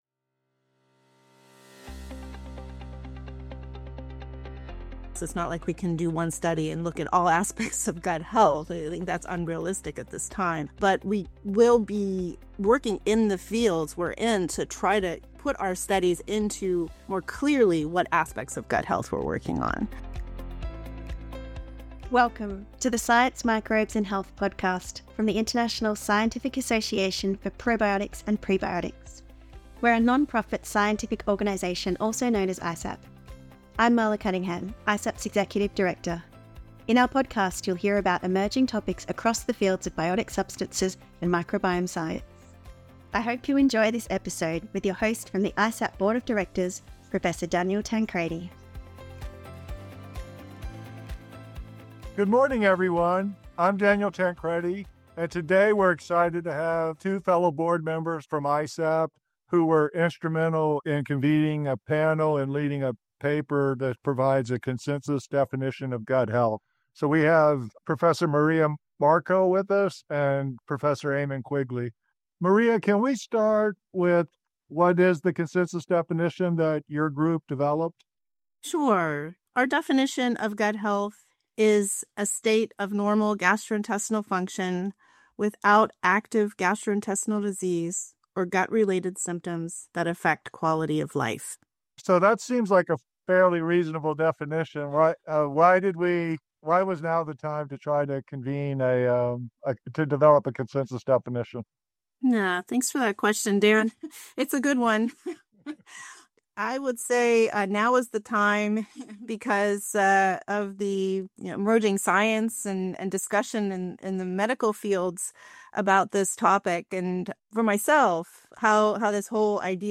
RSS This episode features two guests from the ISAPP board of directors who led the recently published consensus definition of gut health